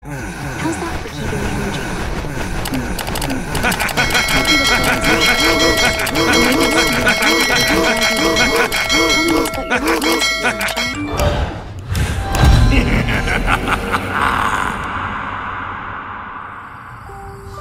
Hello-Neighbor-Like-and-Subscribe-meme-sound-effect.mp3